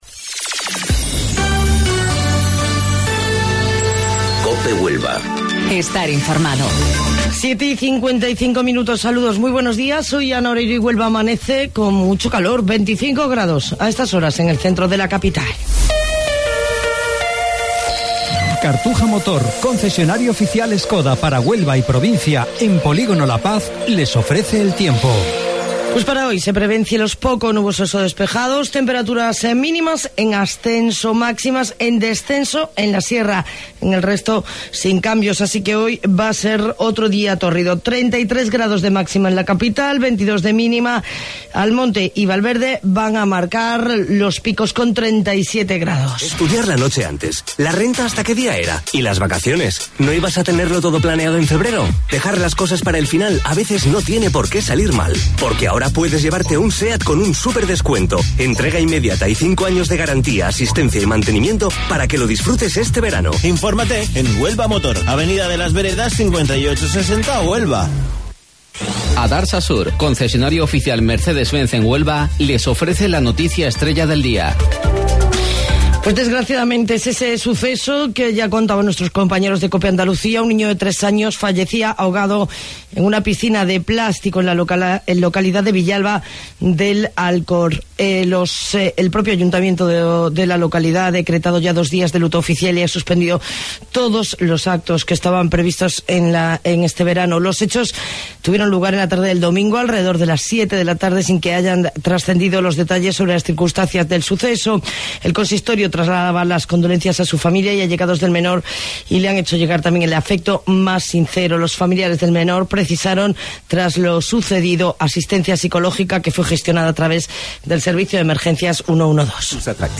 AUDIO: Informativo Local 07:55 del 23 de Julio